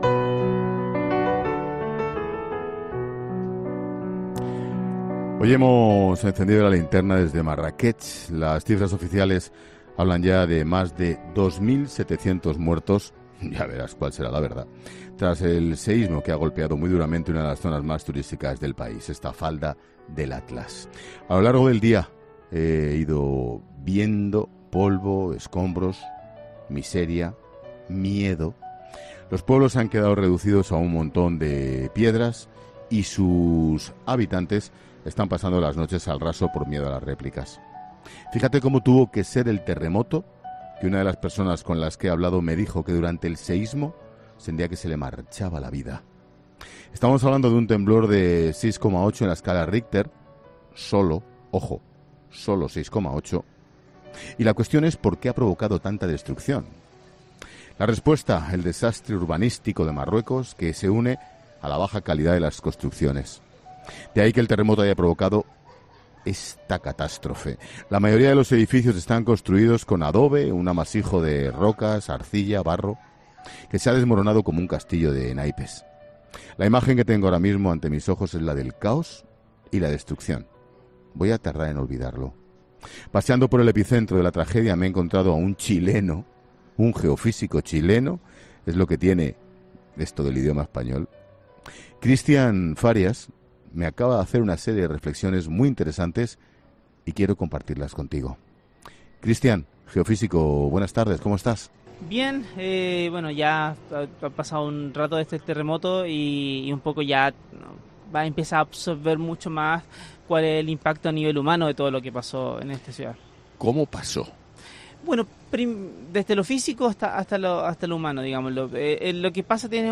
¿Por qué los edificios de Marrakech no han aguantado el terremoto? Un geofísico lo explica en COPE
Ángel Expósito enciende La Linterna desde el epicentro del terremoto: Es imposible reconstruirlo